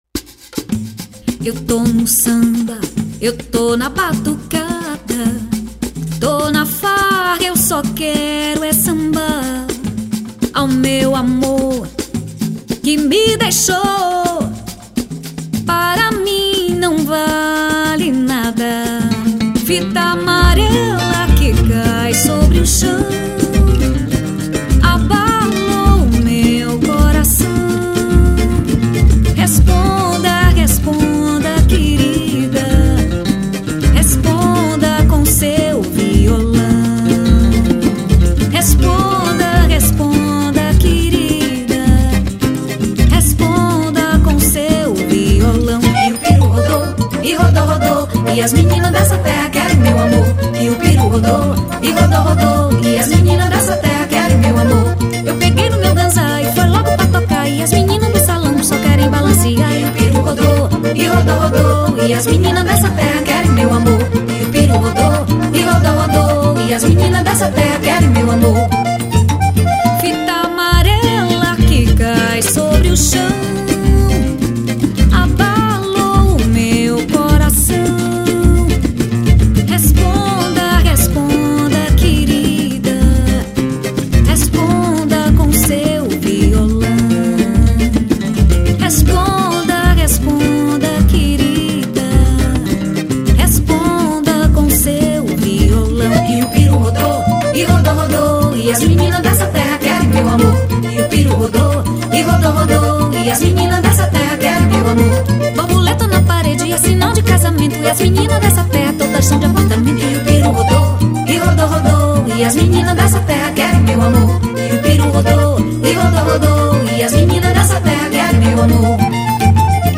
2799   02:31:00   Faixa: 8    Samba